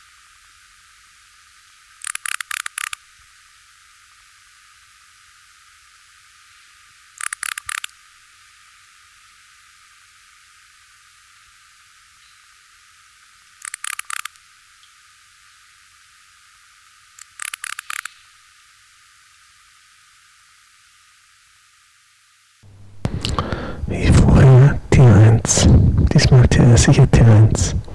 Data resource Xeno-canto - Orthoptera sounds from around the world